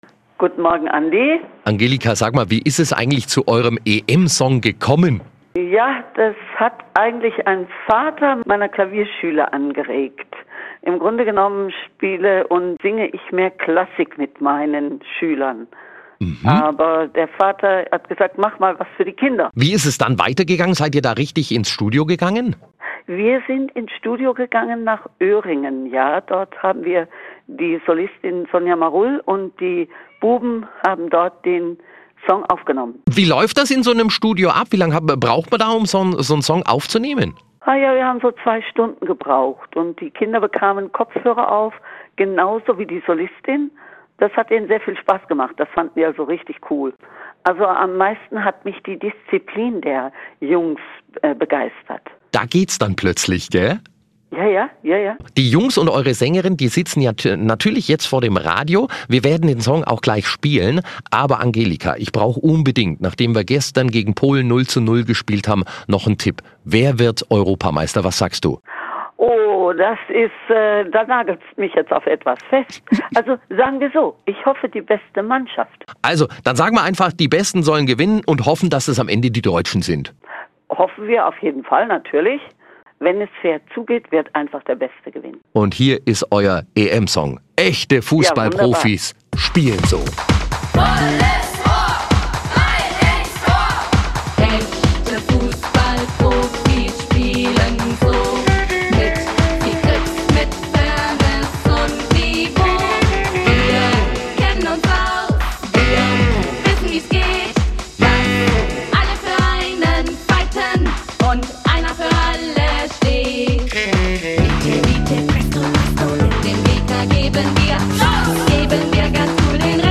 Interview zum Fussballsong